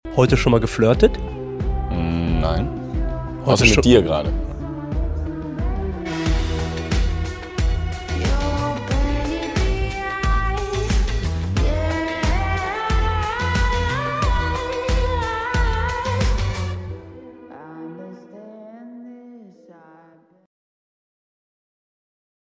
obsessed with this interview till I die istg